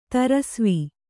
♪ tarasvi